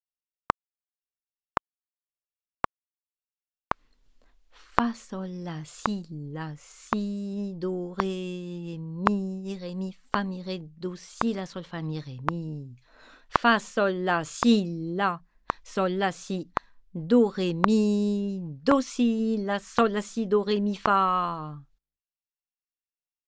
47 - Rythme 01